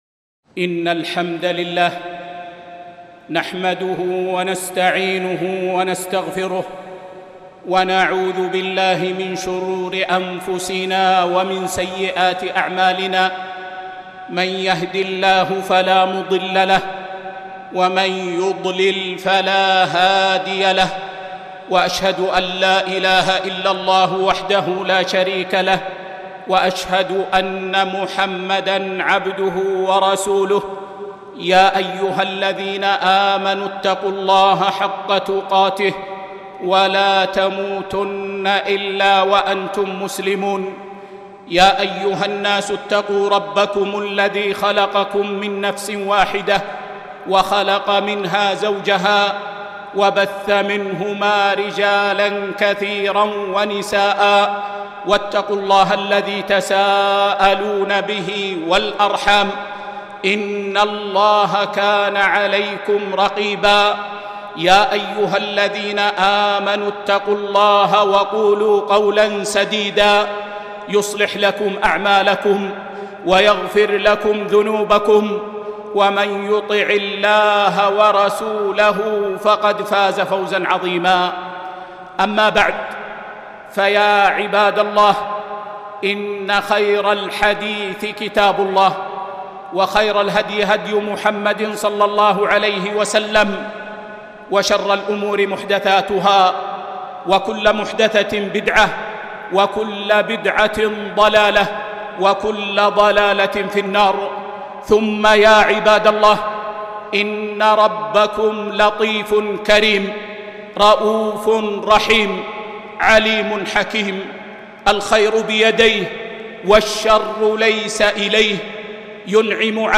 خطبة - من دروس الجائحة